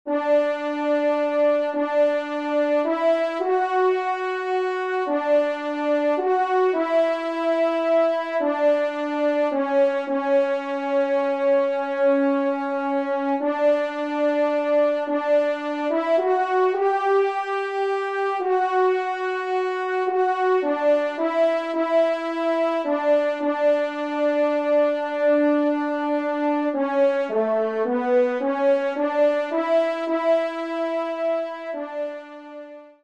1e Trompe ou Cor